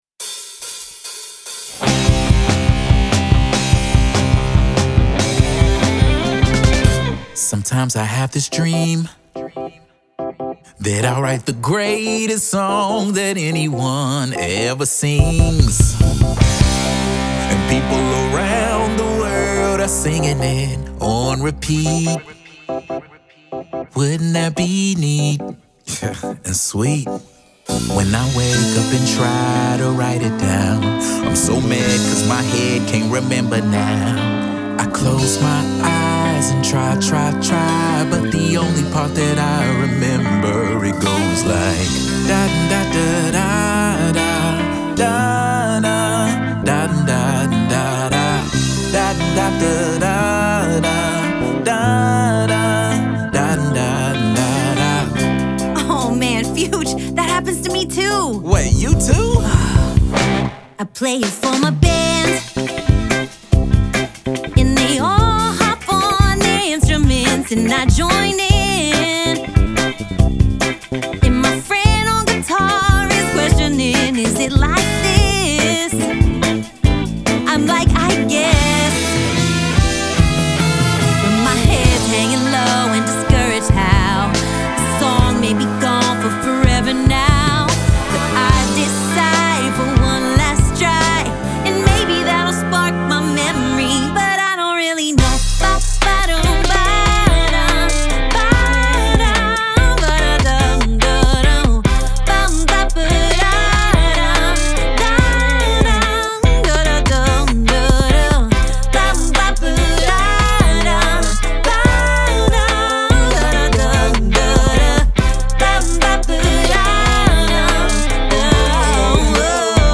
Children's